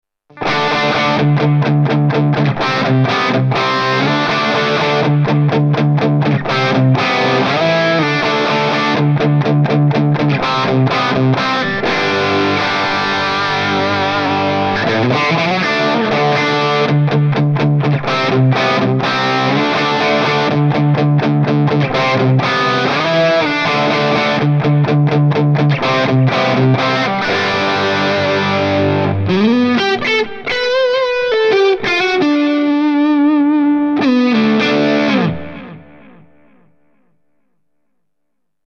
This Amp Clone rig pack is made from a Fuchs ODS 50 amp.
IR USED: MARSHALL 1960A V30 SM57+ E906 POS 1
RAW AUDIO CLIPS ONLY, NO POST-PROCESSING EFFECTS